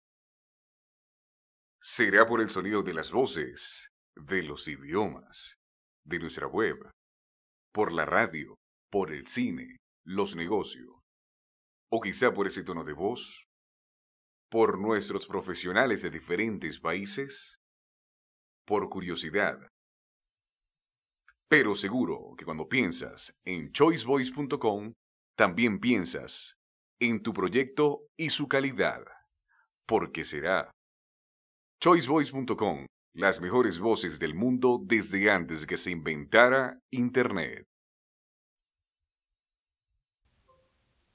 Voz grave, ideal para radio y locuciones que impliquen acento neutro y seriedad.
Sprechprobe: Industrie (Muttersprache):
serious voice, serious, neutral accent. Dubbing, handling of nuances. Voice of radio announcer.